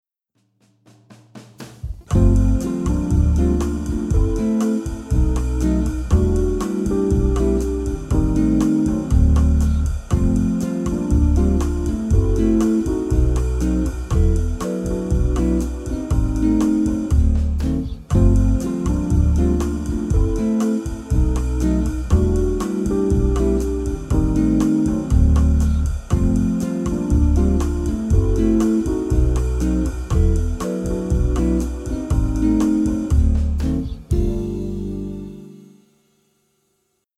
Akkordprogression med modulation og gehørsimprovisation:
Lyt efter bassen, der ofte spiller grundtonen.
Modulation: En lille terts op eller en lille terts ned
C instrument (demo)